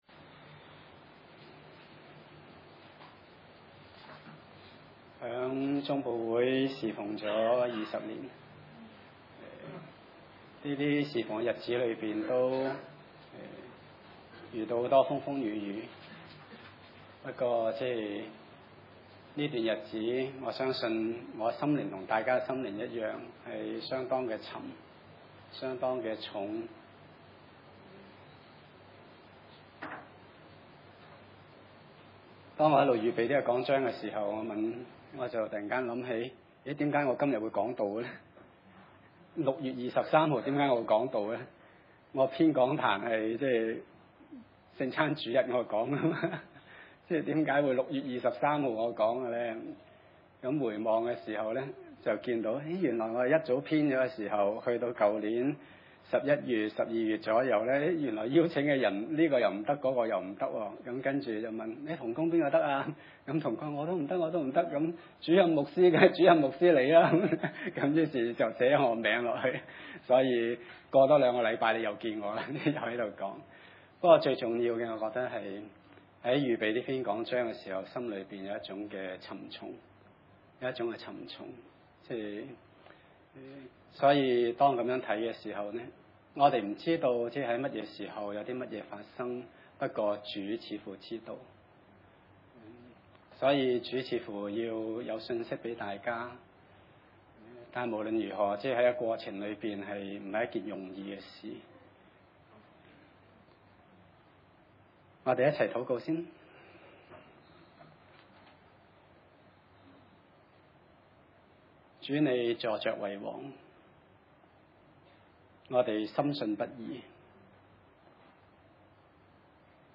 馬太福音 18 1-10 崇拜類別: 主日午堂崇拜 1 當時，門徒進前來，問耶穌說：「天國裏誰是最大的？」